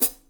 hihat-foot.wav